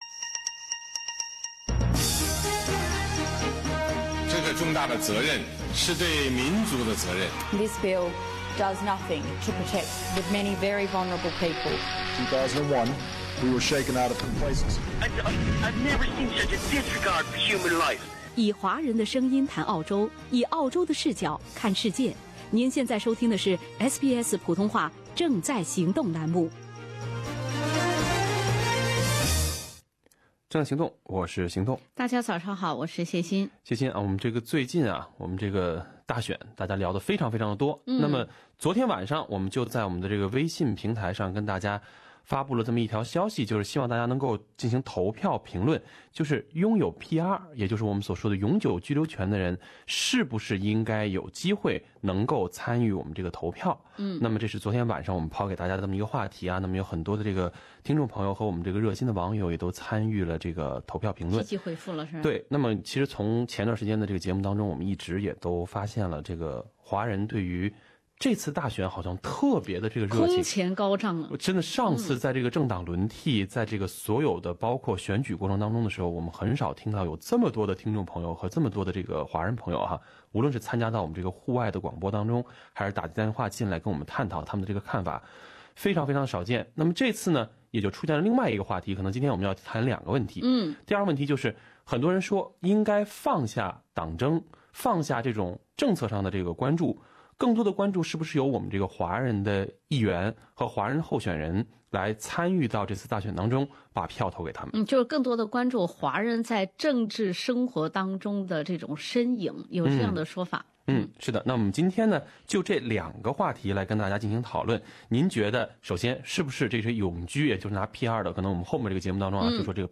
华人听众热议2016澳大利亚联邦大选。